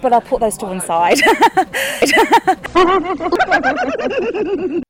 Recently, my laugh was compared to that of Wilma Flintstone.
The evidence it clear – compiled by Kings Lynn Online, the recording starts with me and finishes with Wilma….